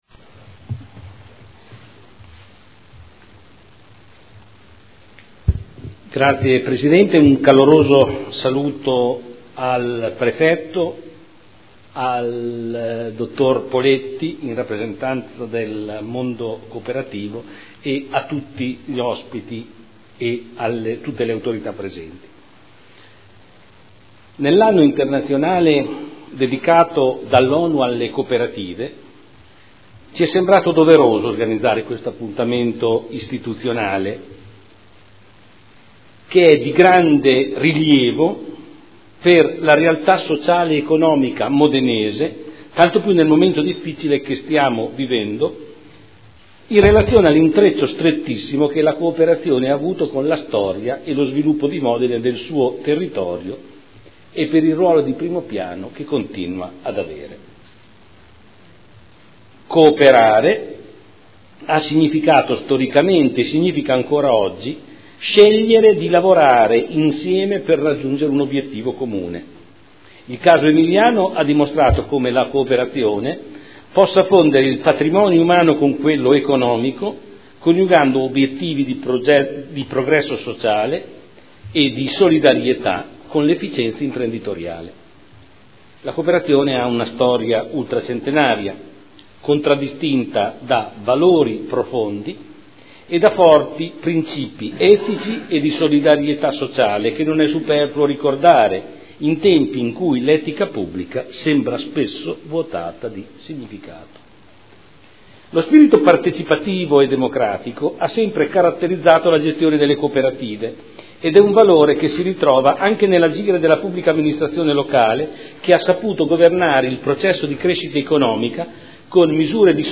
Giorgio Pighi — Sito Audio Consiglio Comunale